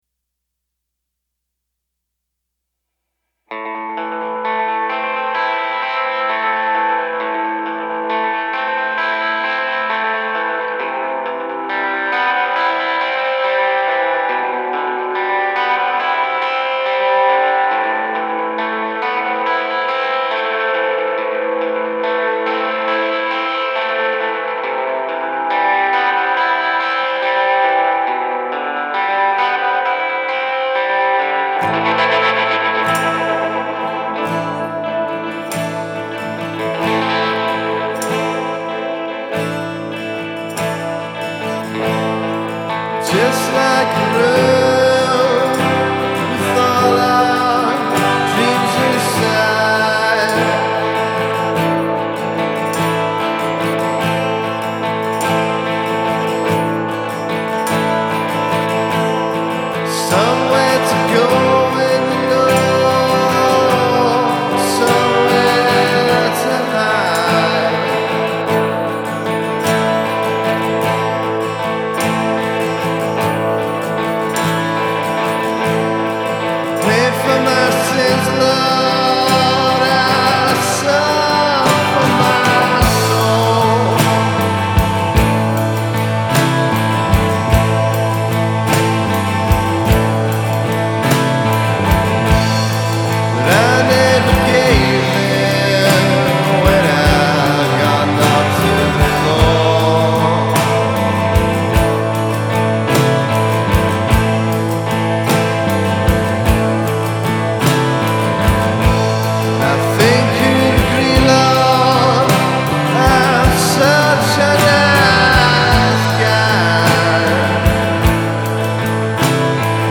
Genre: Indie/Psychedelic-Rock